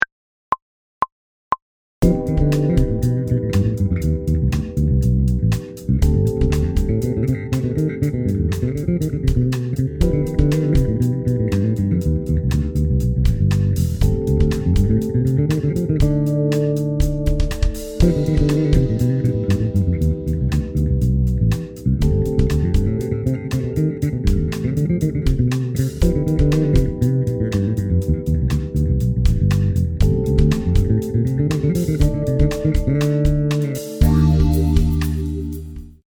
Näide nr 5 (120 BPM) fono: